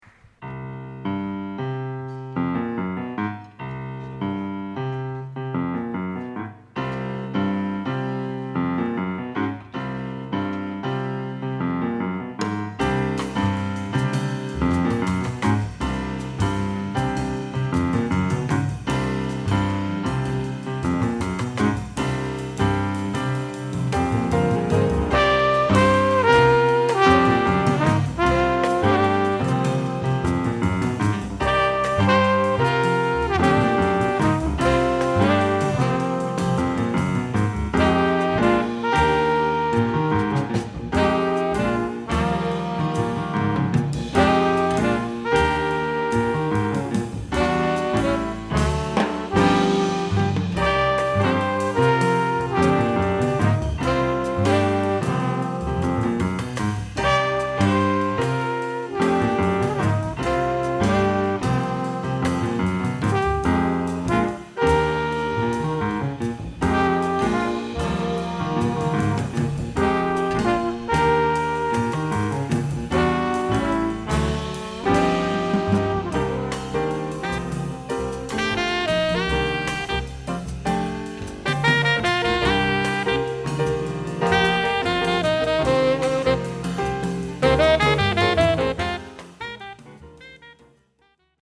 Genres: Jazz, Live.